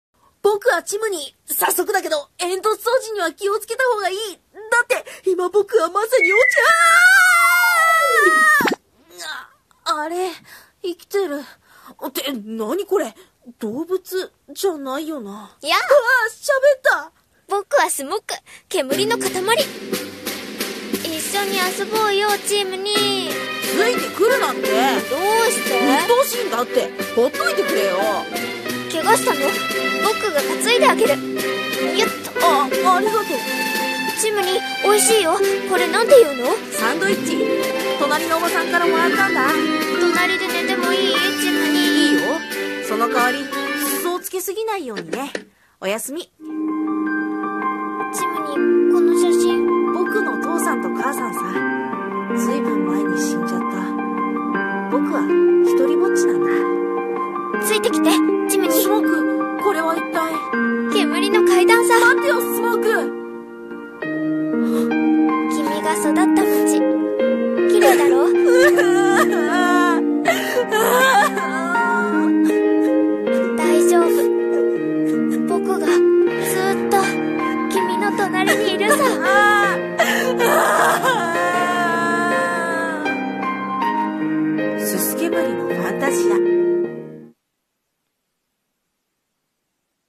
【CM風声劇】煤煙のファンタジア